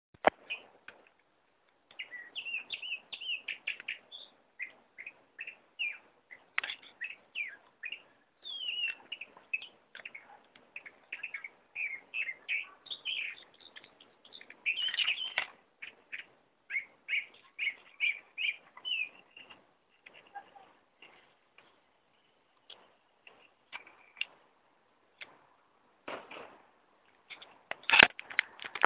Calandria Grande (Mimus saturninus)
Nombre en inglés: Chalk-browed Mockingbird
Fase de la vida: Adulto
Localidad o área protegida: Reserva Ecológica Vicente López
Condición: Silvestre
Certeza: Observada, Vocalización Grabada